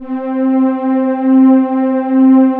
20 PAD    -R.wav